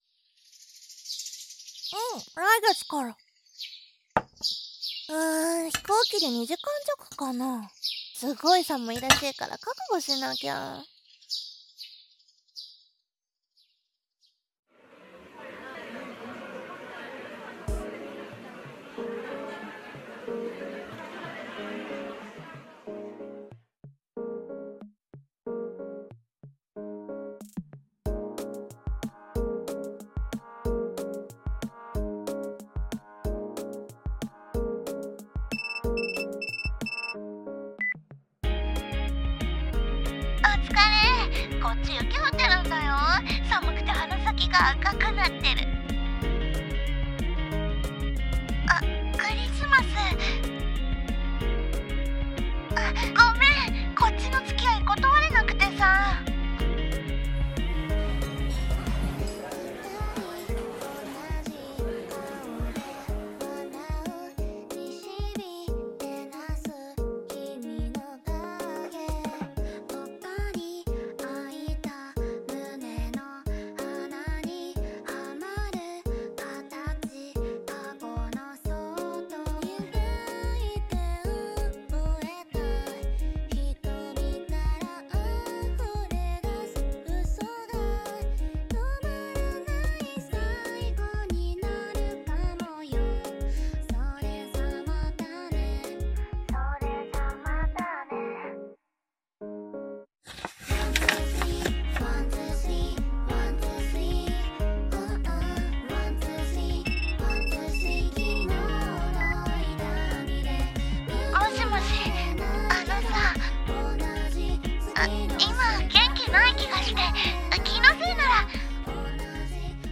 error 【3人声劇】